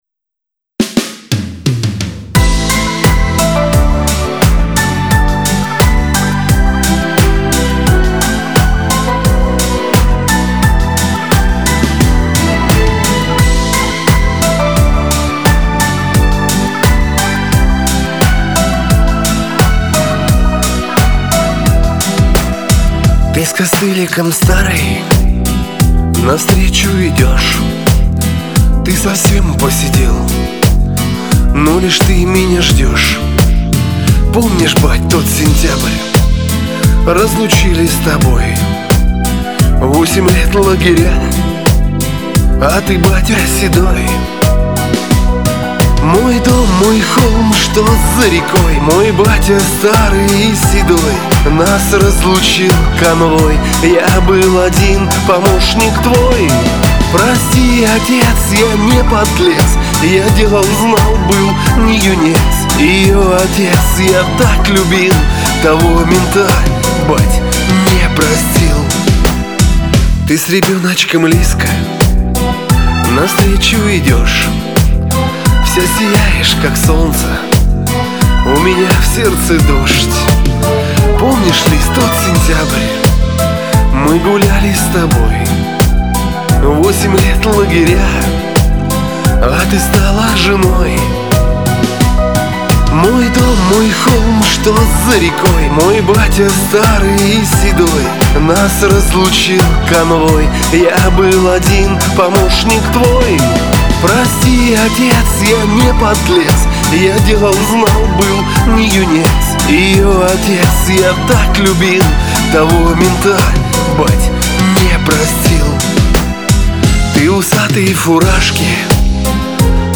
Жанр: Chanson